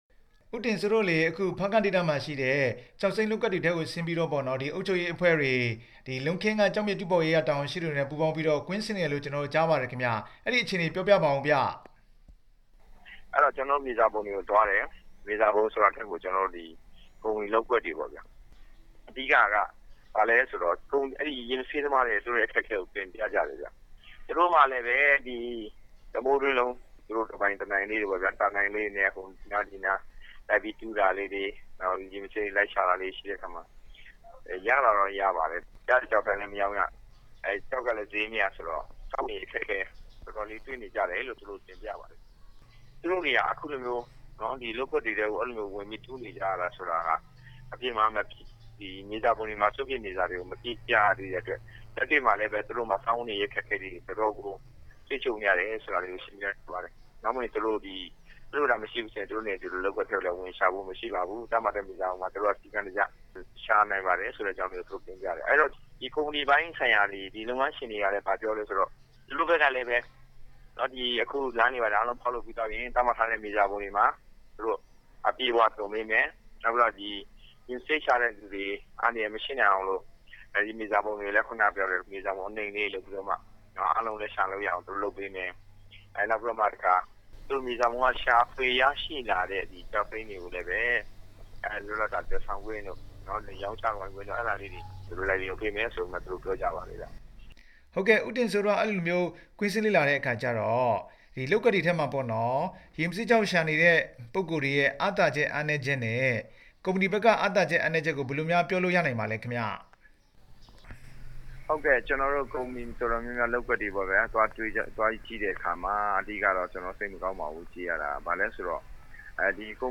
ကျောက်စိမ်းလုပ်ကွက်တွေကို သွားရောက်လေ့လာခဲ့တဲ့ ဦးတင့်စိုးနဲ့ မေးမြန်းချက်